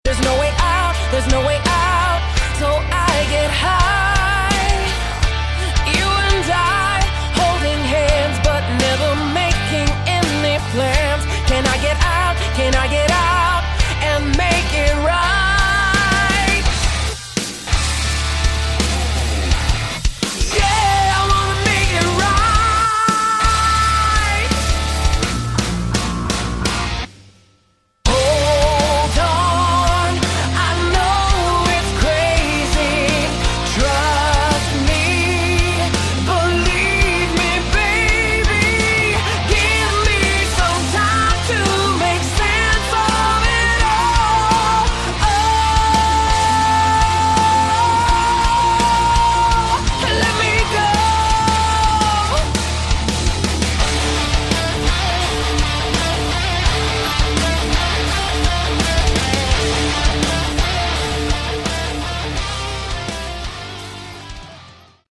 Category: Melodic Metal
bass, keyboards
guitar
drums
backing vocals